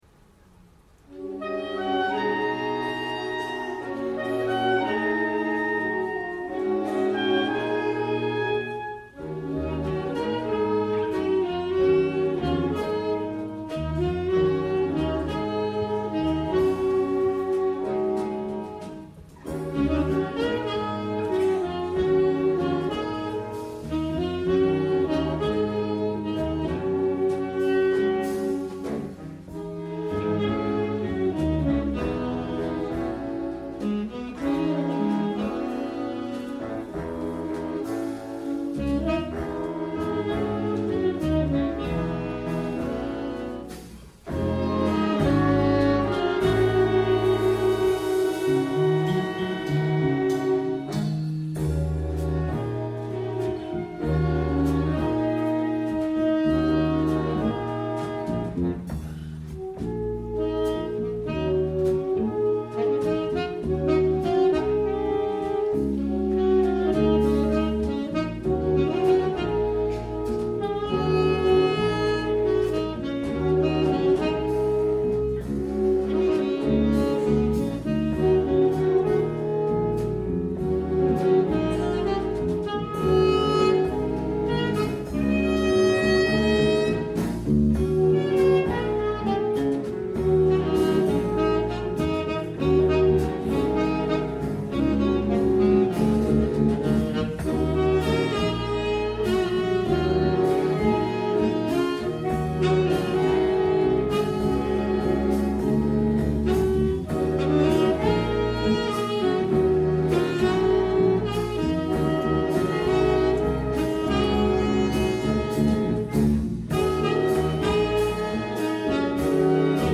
Summer Concert 2014